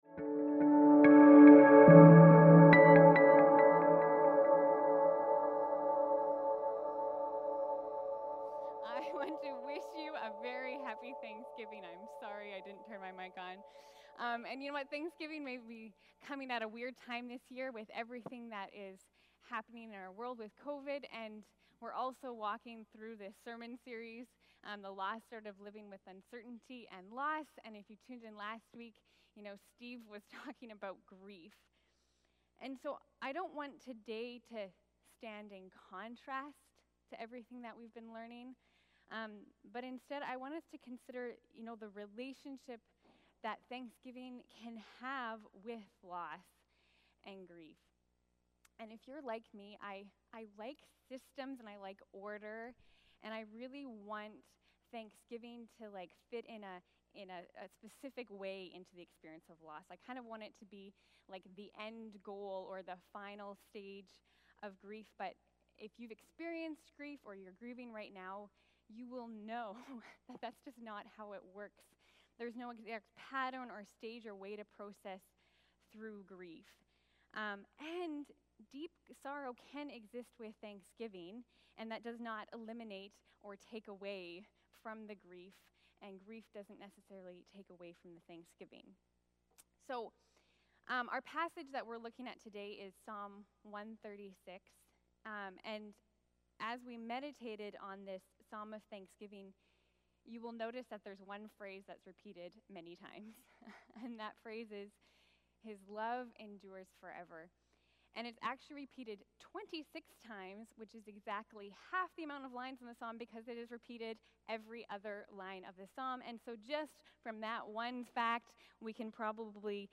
Sermons | Saanich Baptist Church